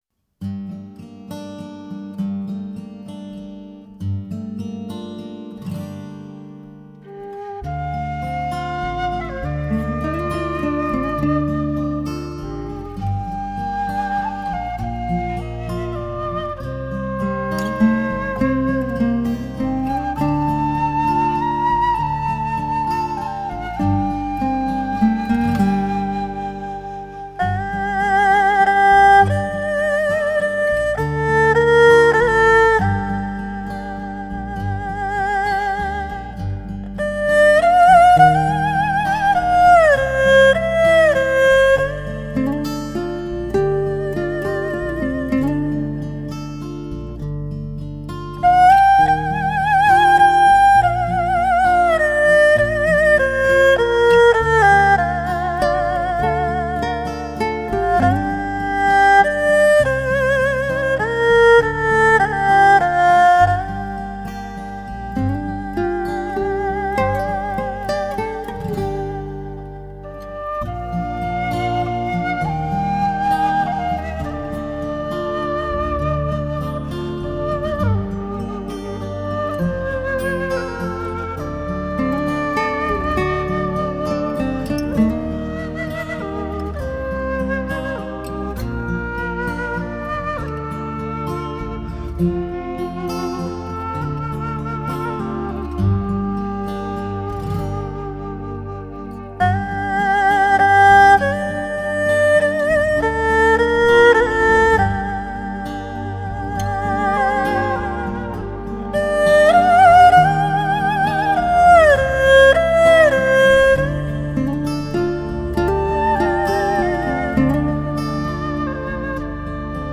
器乐：二胡
类型：纯音乐
十幾種中西樂器:古箏，琵琶，笛子，長笛，黑管，弦樂，吉他等。